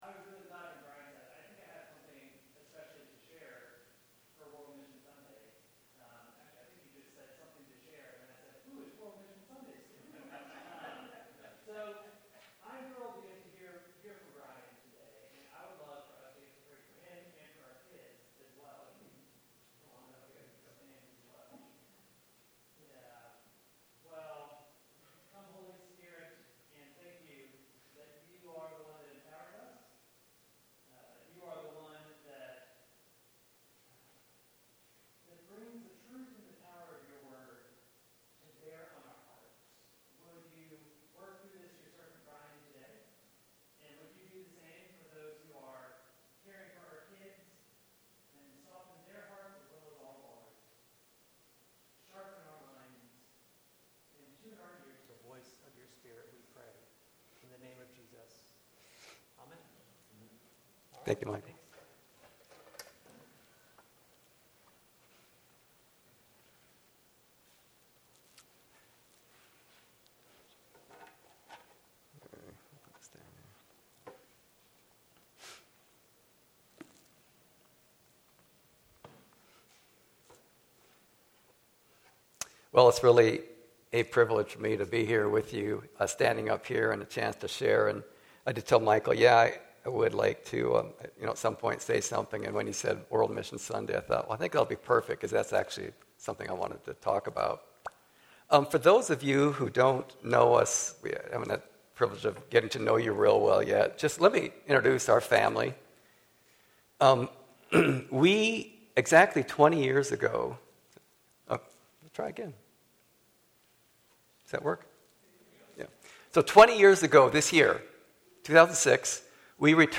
World Mission Sunday (delayed a week by weather)